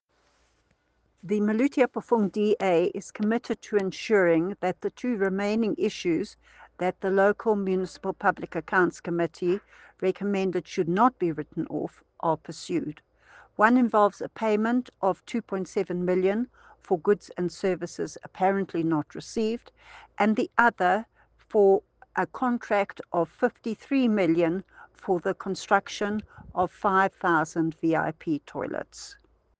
English soundbite by Cllr Alison Oates,